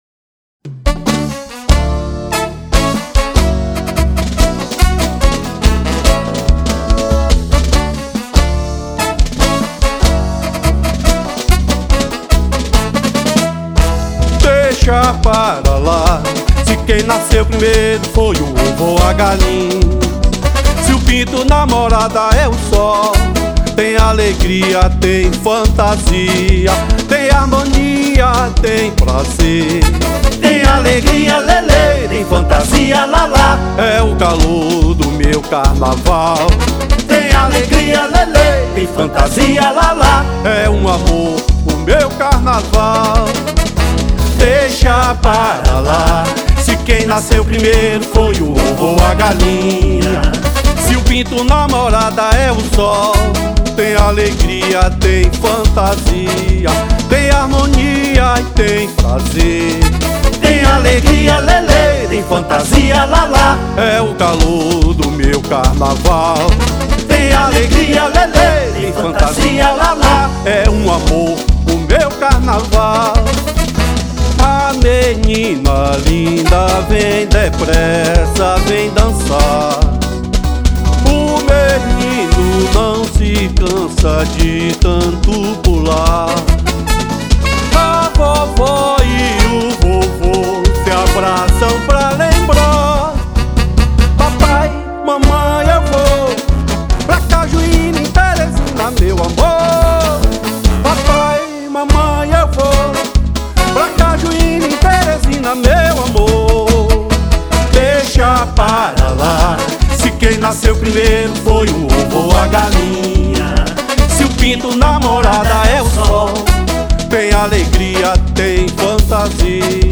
615   03:05:00   Faixa:     Frevo